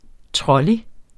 Udtale [ ˈtʁʌli ]